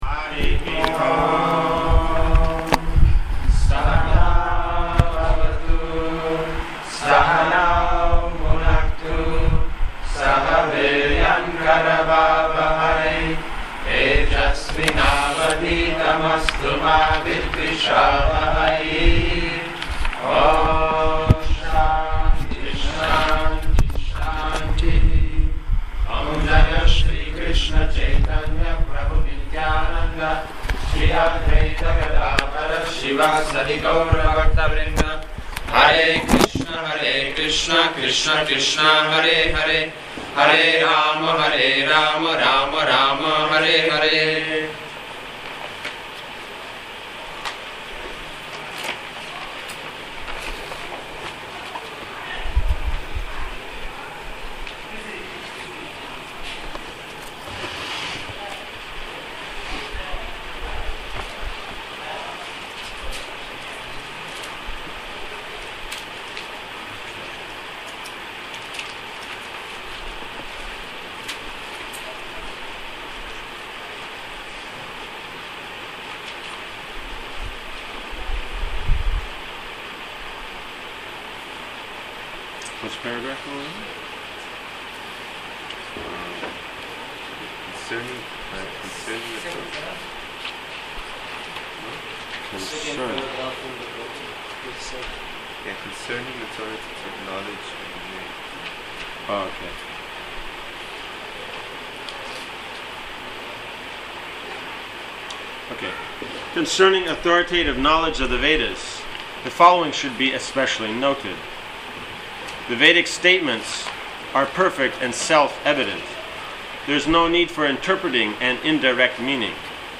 Transcription of lecture #2 of Śrī Śrī Caitaya Śikṣāmṛta and Daśa Mūla Tattva course, given in Bhaktivedānta Academy, Śrī Māyāpura